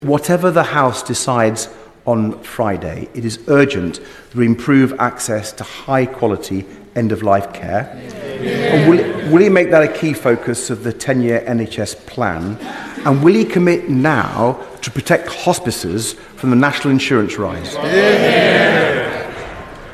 Lib Dem Leader and MP for Kingston and Surbiton, Sir Ed Davey at PMQs today